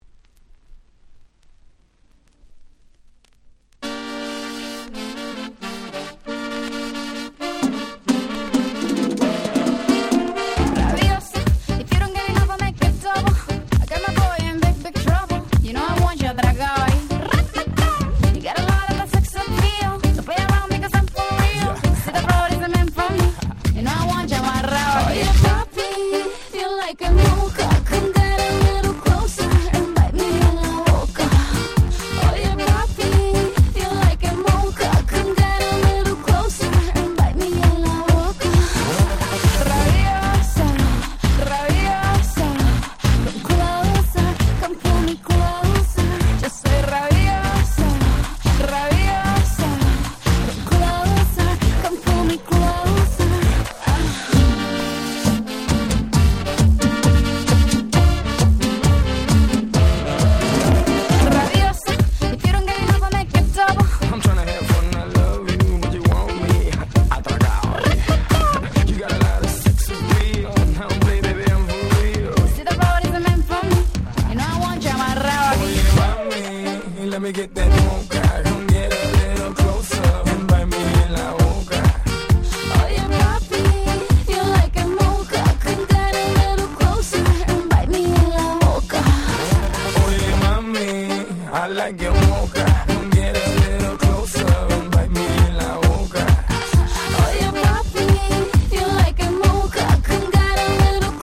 11' Smash Hit R&B / Pops !!